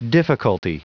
Prononciation du mot difficulty en anglais (fichier audio)
Prononciation du mot : difficulty